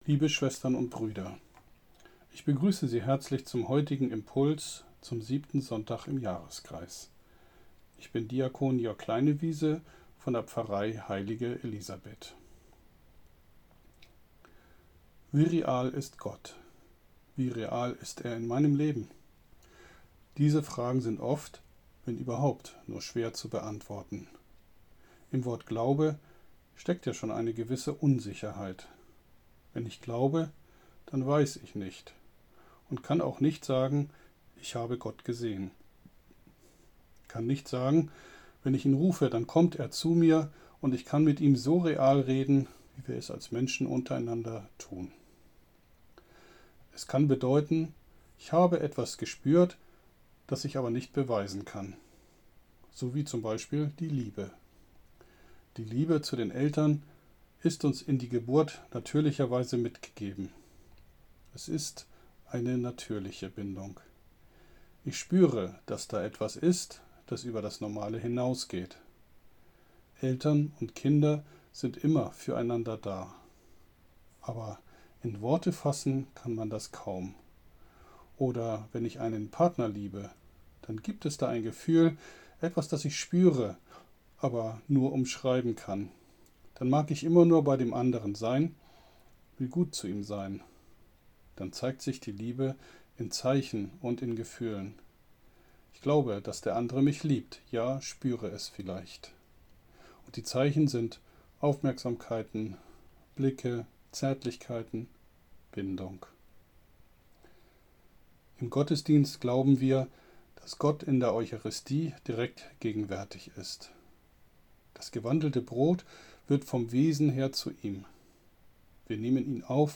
Gottes Liebe wirkt durch mich – Sonntagsimpuls zum 20. Februar 2022